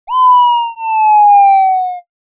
added drop sound.
drop.wav